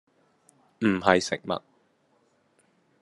Голоса - Гонконгский 14